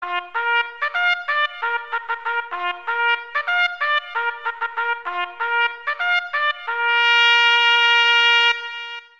Jagdhorn klingelton